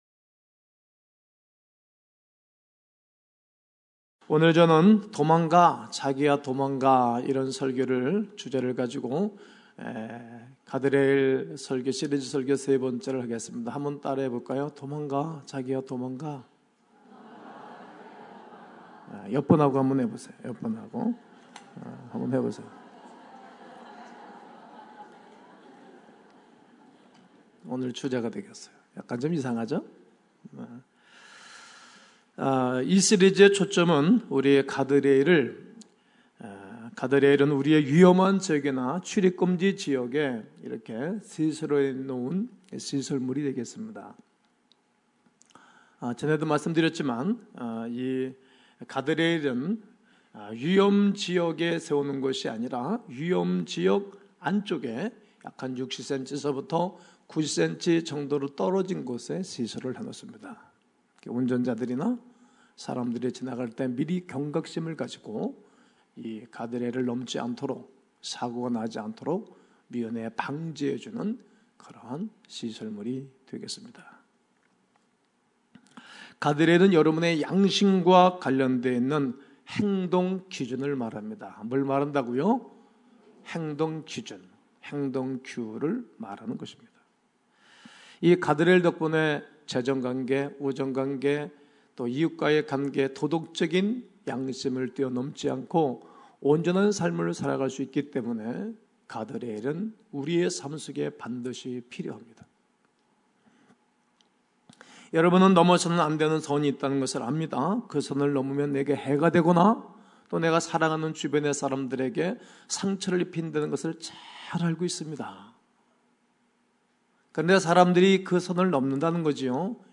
음성